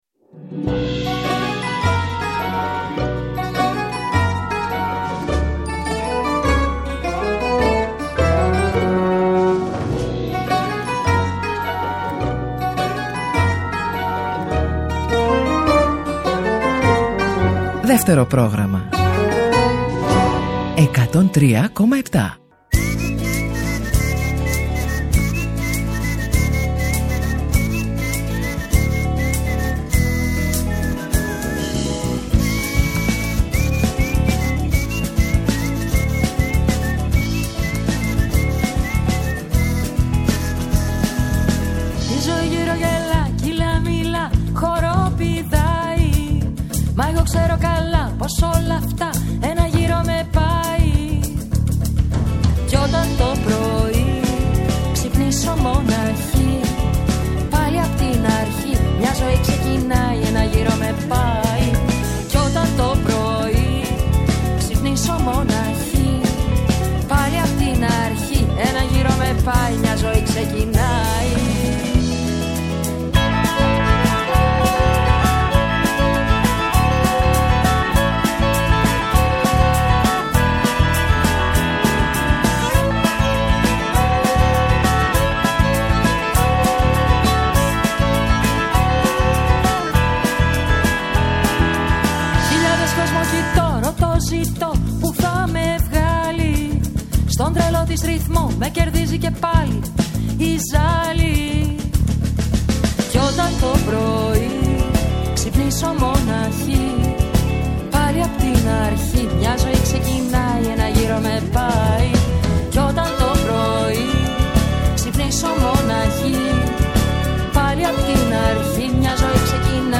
Στο “Μελωδικό Αντίδοτο” oι καινούριες μουσικές κάνουν παρέα με τις παλιές αγαπημένες κάθε σαββατοκύριακο.